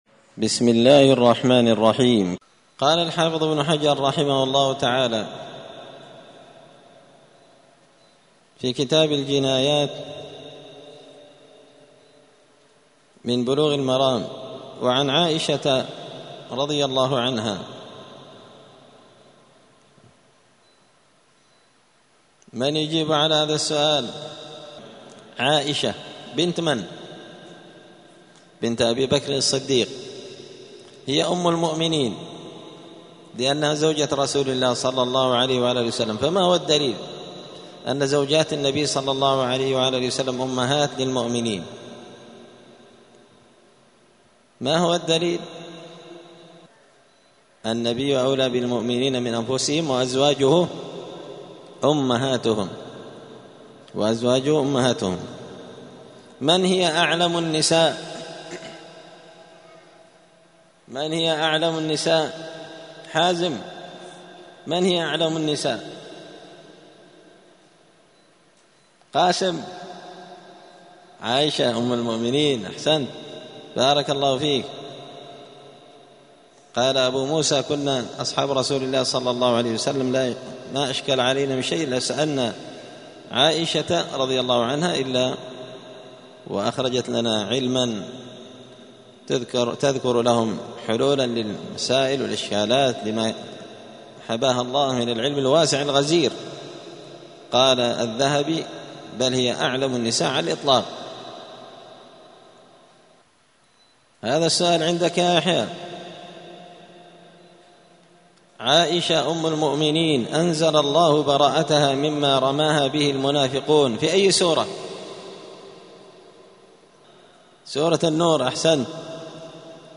*الدرس الثاني (2) {باب الجنايات}*